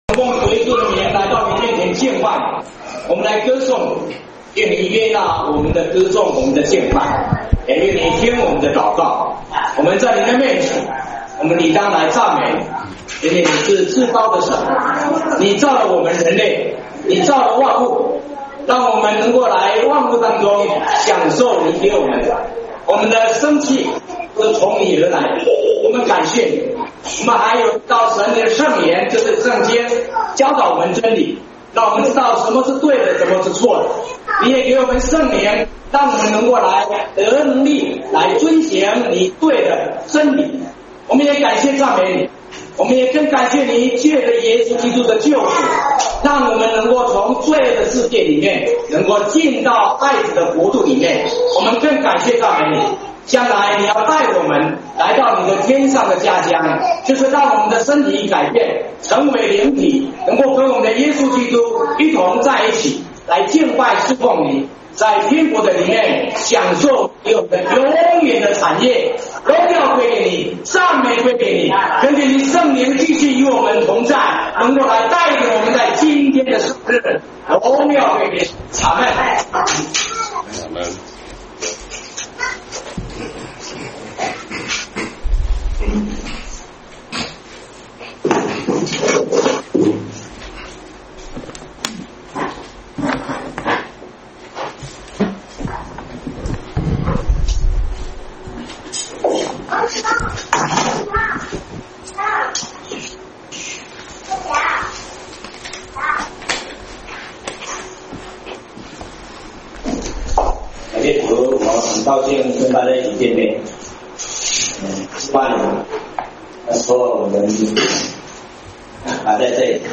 詩頌